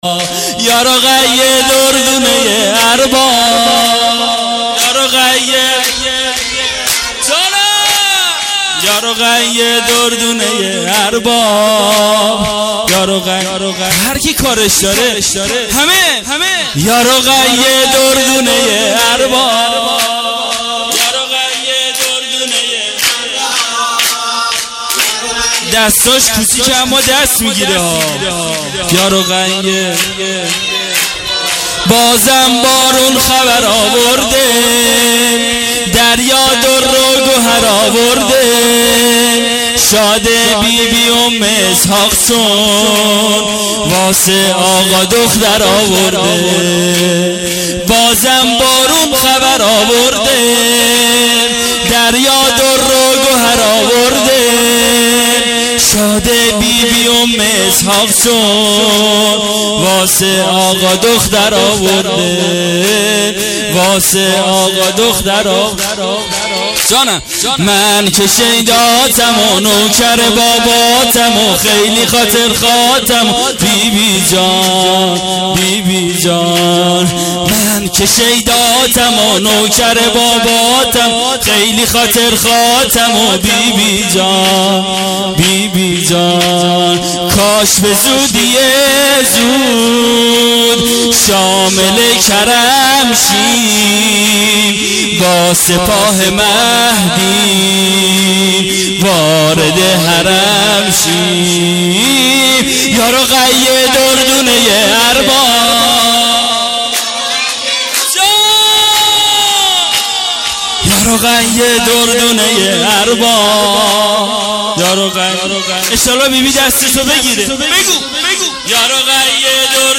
جشن میلاد حضرت رقیه(س) ۹۸